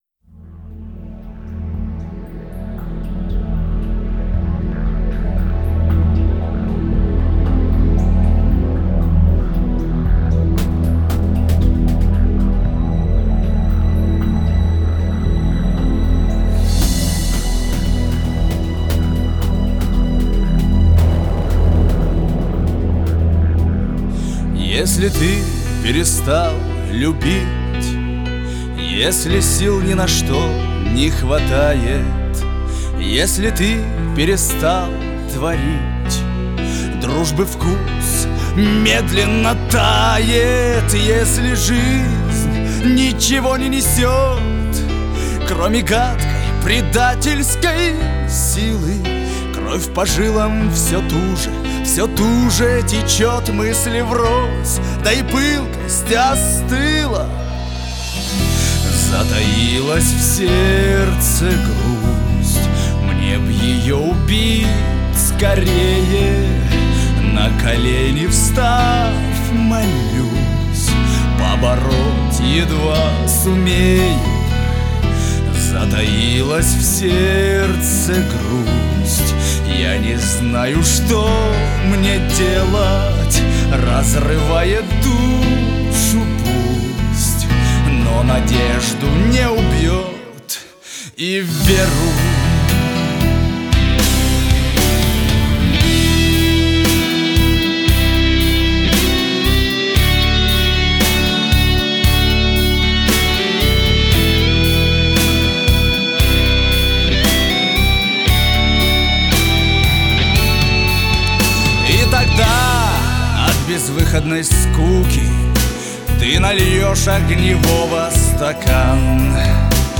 Несмотря на название, очень лиричная песня)